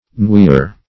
noier - definition of noier - synonyms, pronunciation, spelling from Free Dictionary Search Result for " noier" : The Collaborative International Dictionary of English v.0.48: Noier \Noi"er\, n. An annoyer.